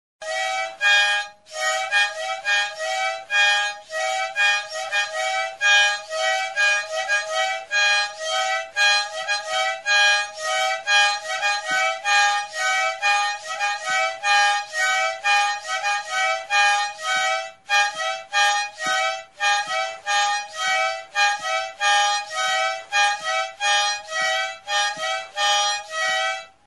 Music instrumentsAKORDEOIA; Akordeoi jostailua
Aerophones -> Reeds -> Single Free
Recorded with this music instrument.
Bi nota baino ez du ematen, ireki edo ixterakoan.